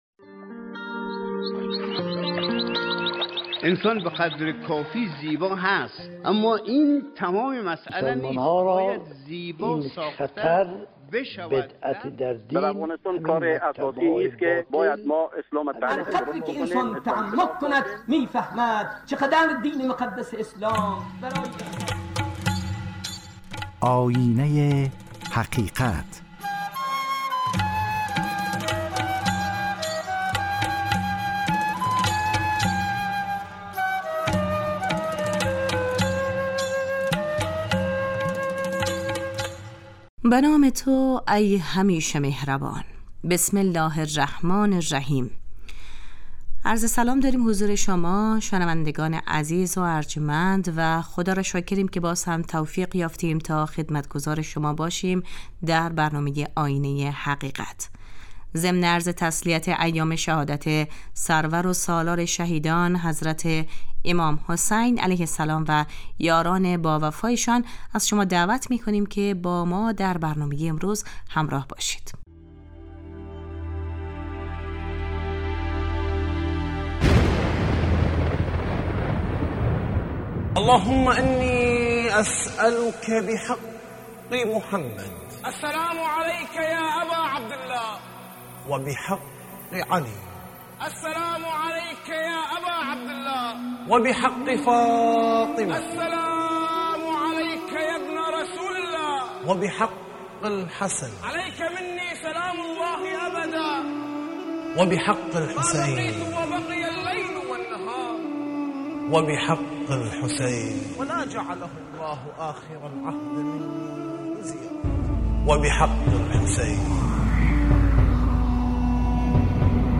آیینه حقیقت ( پرسش و پاسخ های اعتقادی )